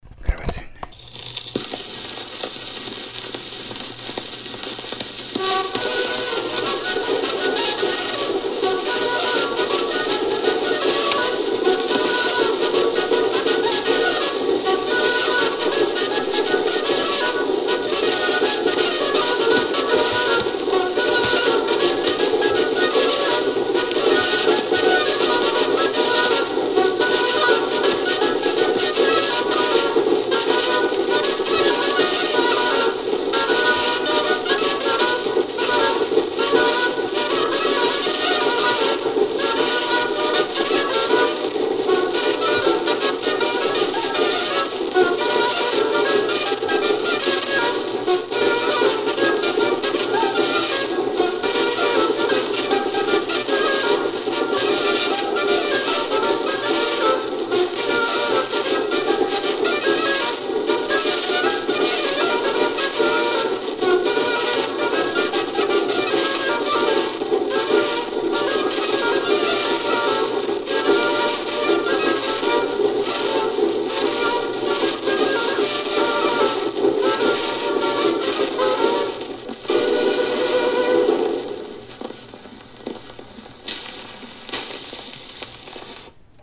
Aparatos de reproducción -> Gramófono / fonógrafo / gramola
ARIÑ-ARIÑ. Por los Gaiteros de Regil.
Grabado con este instrumento.